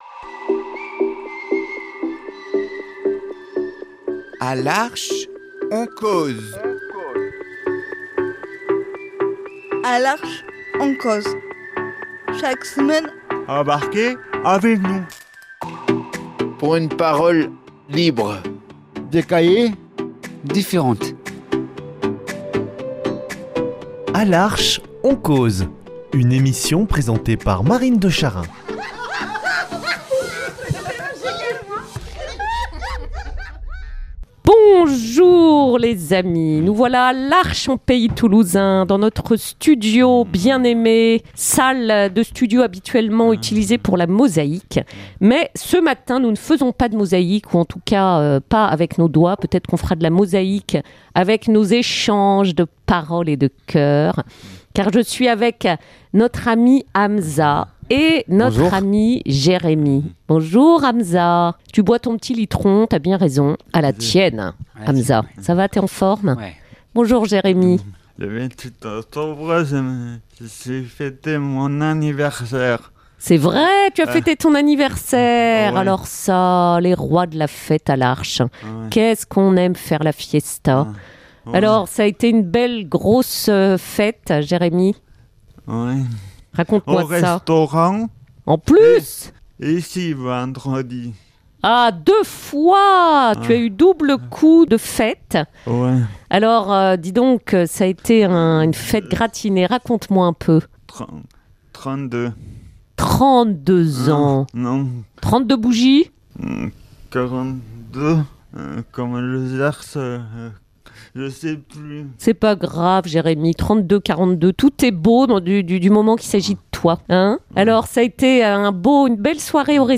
Leurs confidences ont de la saveur, leur parole est vraie, simple, tout simplement vivante.